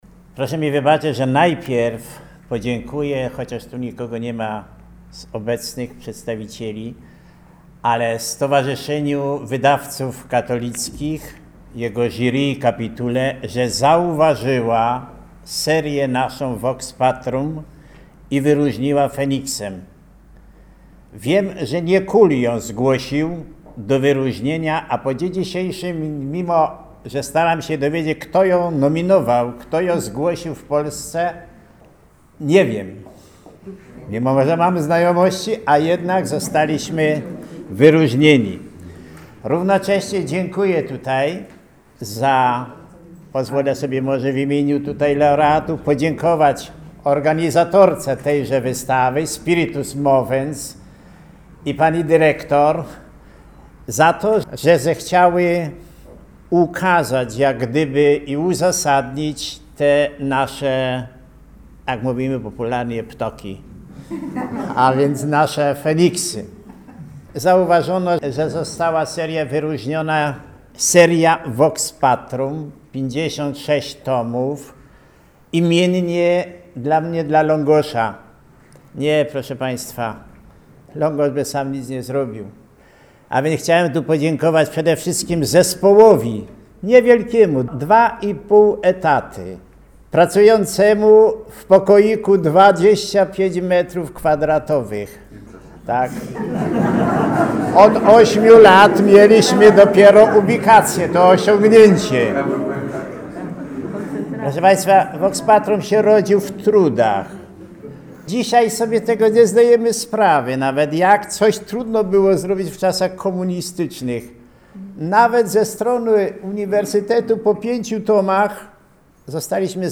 Wystąpienia laureatów